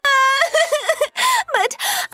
Comic Crying - Botón de Efecto Sonoro